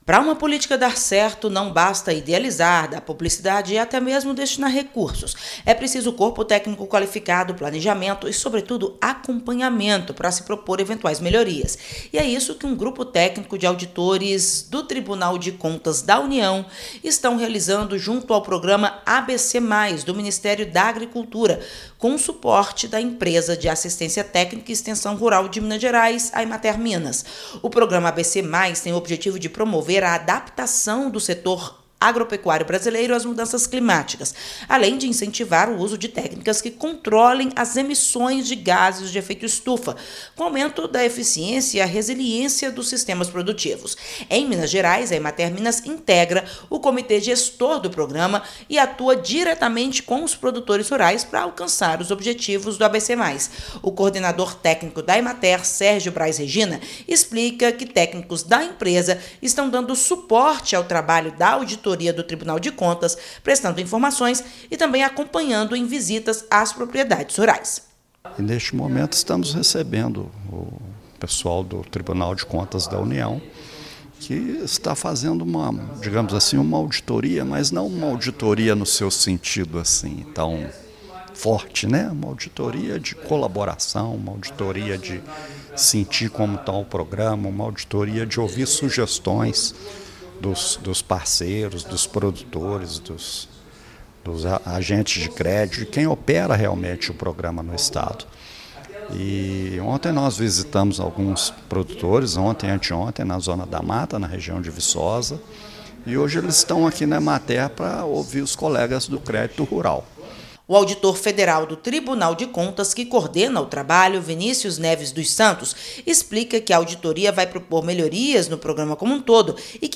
Um grupo de auditores do Tribunal de Contas da União, juntamente com a Emater-MG, visitou produtores rurais em Minas Gerais que fazem parte do Programa ABC Mais. Eles ouviram sugestões para melhoria no programa. Ouça matéria de rádio.